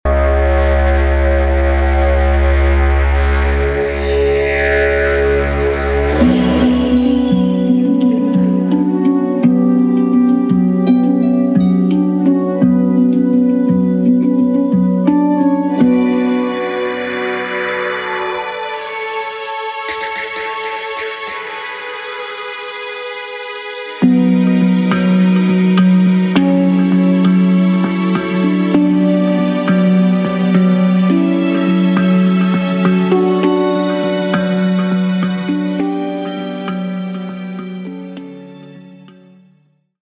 Género: Experimental / Relaxing.